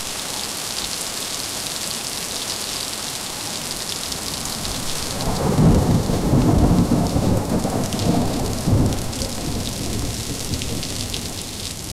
Soda can pouring 0:15 Created Nov 6, 2024 9:58 PM The sound of water being poured into a glass. 0:10 Created Sep 5, 2024 11:34 PM The sound of rain pouring down during a thunderstorm. 0:12 Created Sep 5, 2024 11:34 PM
the-sound-of-rain-pouring-down-during-a-thunderstorm-qkzq5lxi.wav